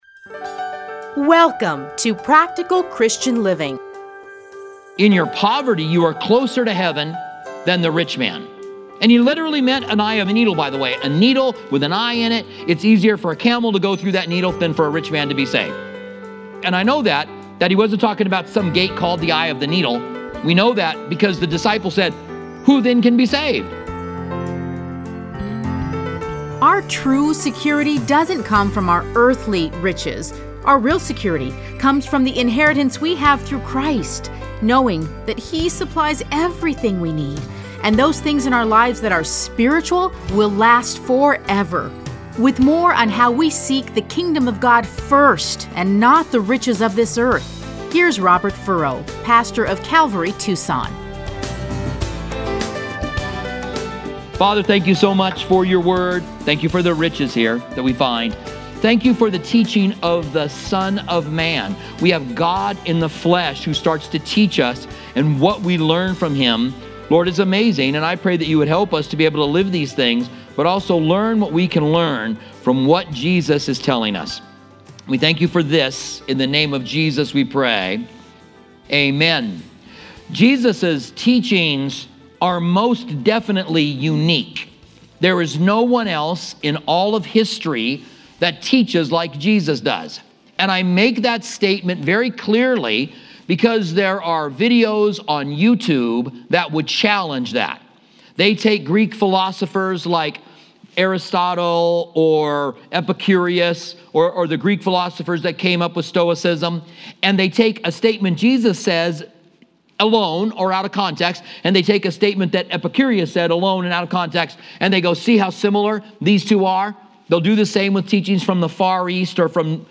Listen to a teaching from Luke 6:20-36.